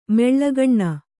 ♪ meḷḷagaṇṇa